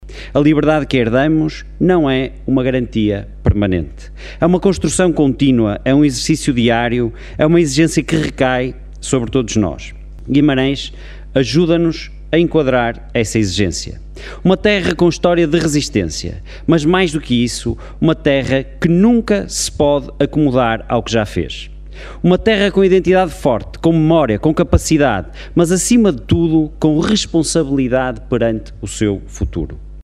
O Teatro Jordão foi o palco da Sessão Solene da Assembleia Municipal de Guimarães, onde se celebraram os 52 anos da Revolução dos Cravos com um apelo renovado à responsabilidade cívica.